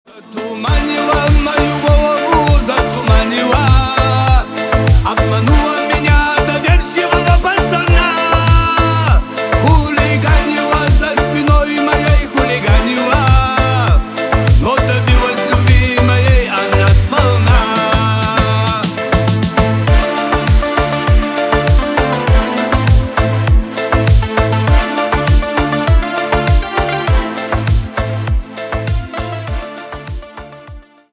на русском восточные про любовь на девушку